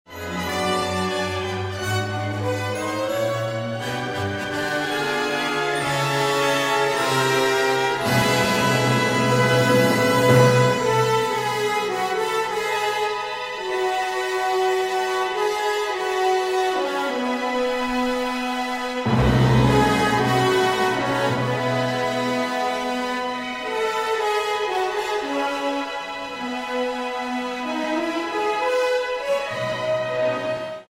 Grammy Award-winning pianist Daniil Trifonov brings his technique, style, poetry and power to one of the most monumental piano concertos ever written. Brahms’ grand Second Piano Concerto has left audiences in awe ever since its premiere in 1881.